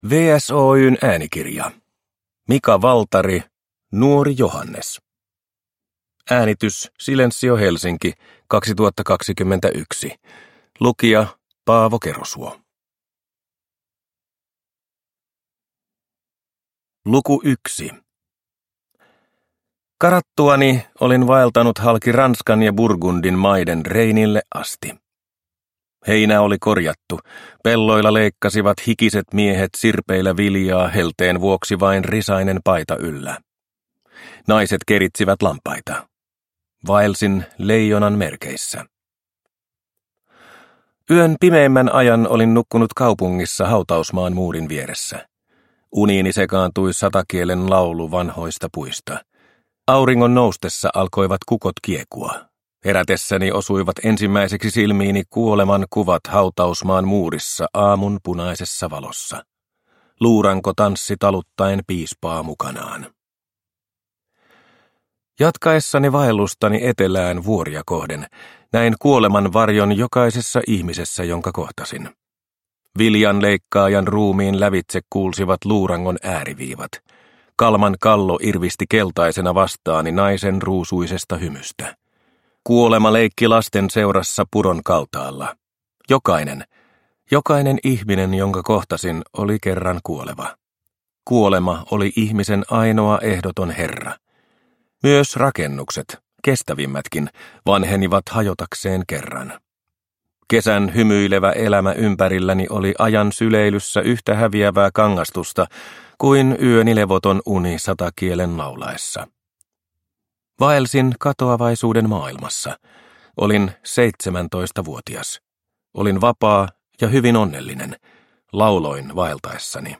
Nuori Johannes – Ljudbok – Laddas ner